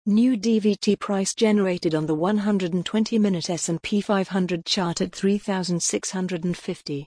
Here's some examples of how that could sound.